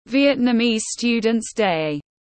Ngày sinh viên Việt Nam tiếng anh gọi là Vietnamese Student’s Day, phiên âm tiếng anh đọc là /ˌvjɛtnəˈmiːz ˈstjuːdənts deɪ/
Vietnamese Student’s Day /ˌvjɛtnəˈmiːz ˈstjuːdənts deɪ/